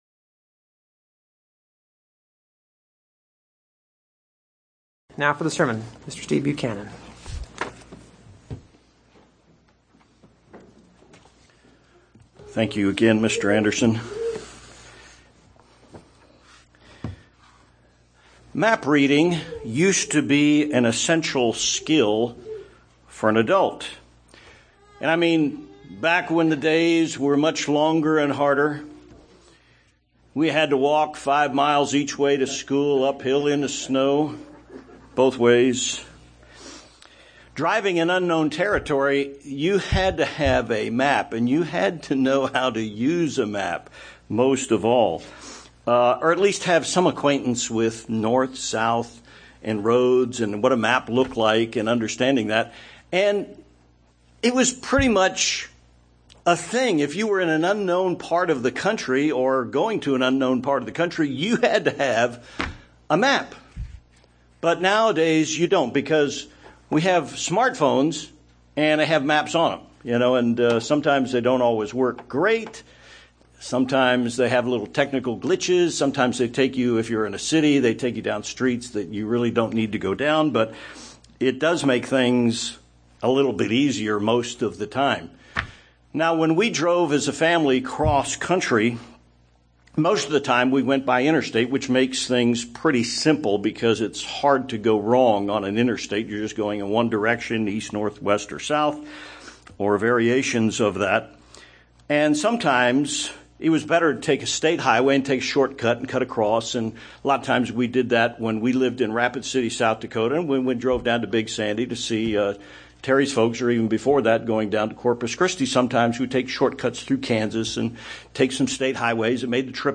Paul indicated to Timothy that the Holy Scriptures help us to be complete. The Bible is a roadmap to perfection! This sermon offers Bible study tips and methods.